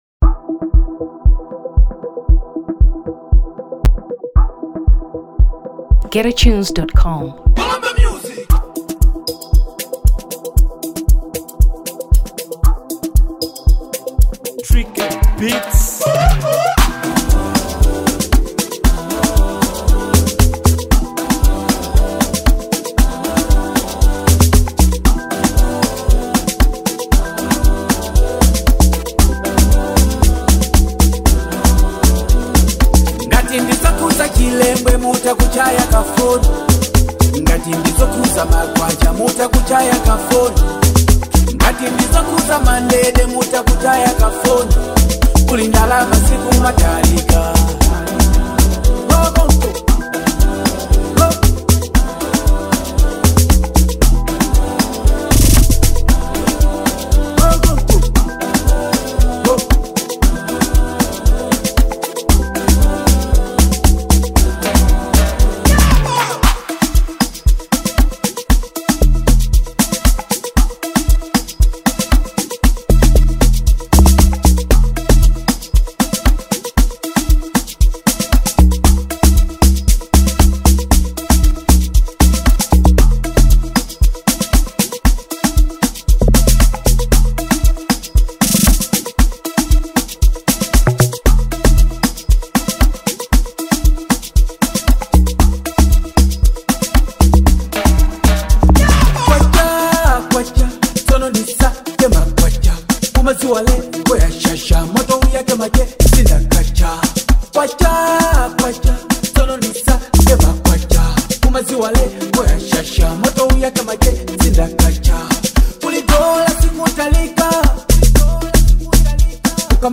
Amapiano 2023 Malawi